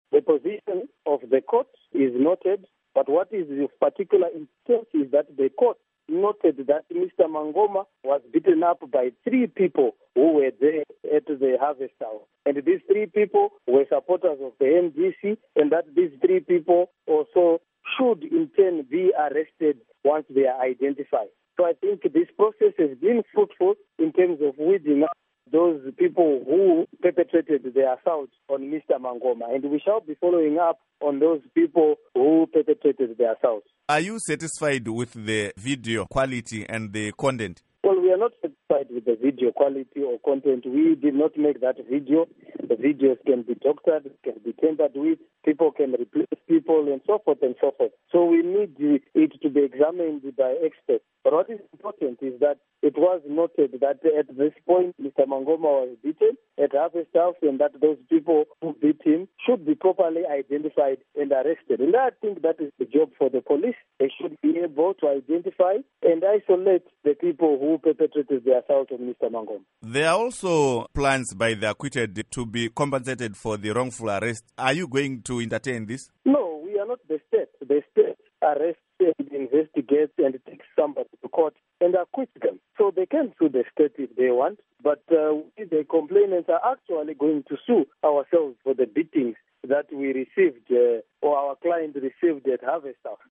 Interview With Jacob Mafume